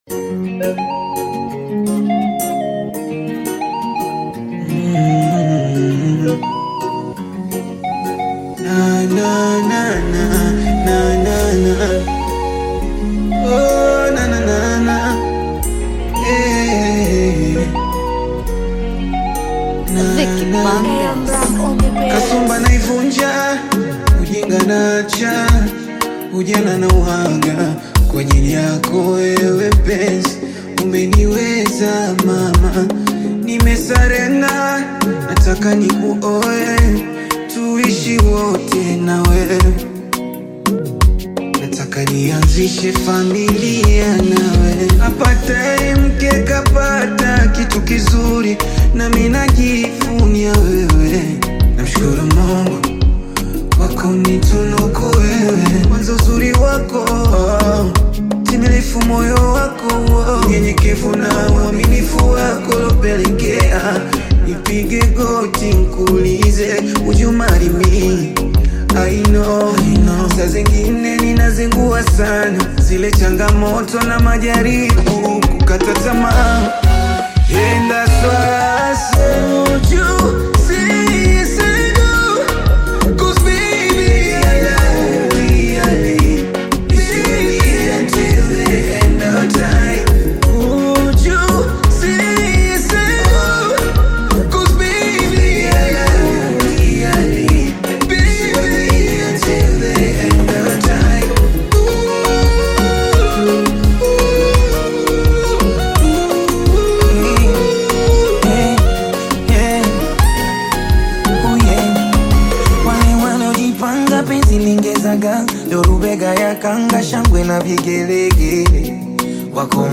Bongo Flava
This catchy new song